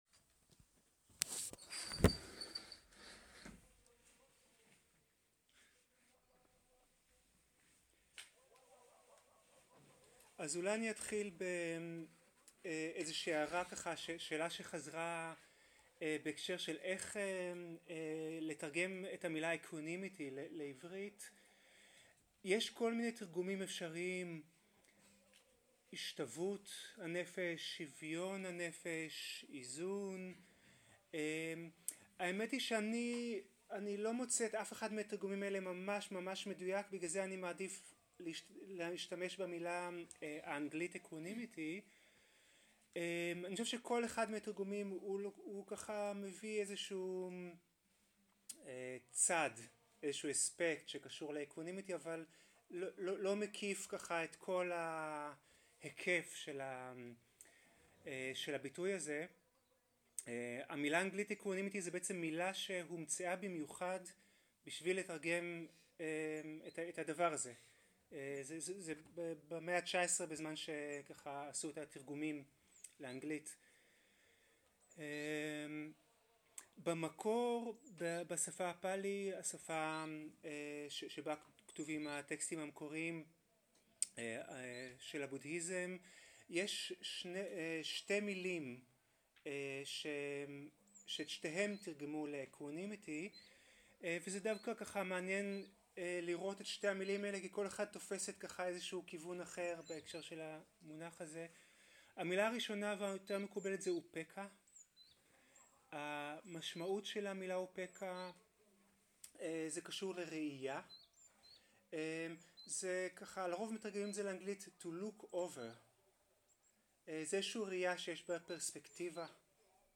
סוג ההקלטה: שיחת הנחיות למדיטציה
איכות ההקלטה: איכות גבוהה